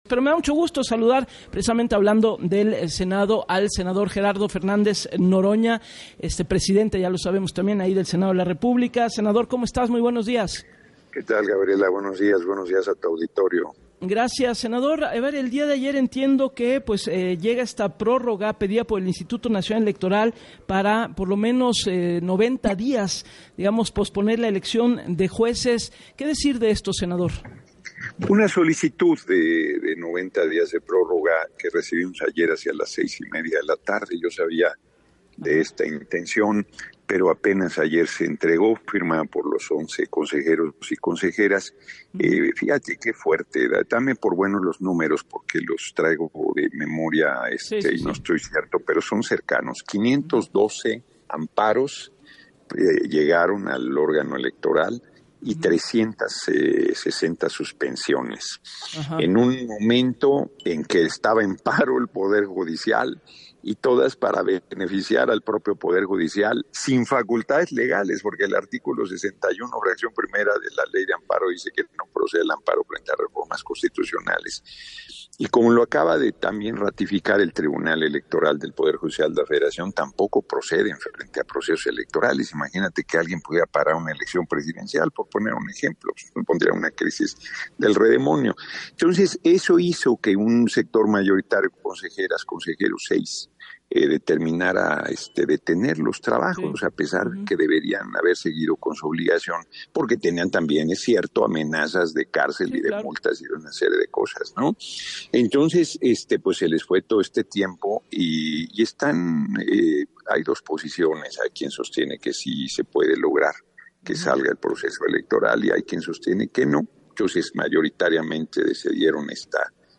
En entrevista para “Así las Cosas” con Gabriela Warkentin, señaló “yo estoy convencido de que debe hacerse el 1 de junio de 2025, yo no tomo esta decisión solo, está también el equipo jurídico analizando todas las implicaciones que esto tiene; tendremos que tomar una decisión en breve”.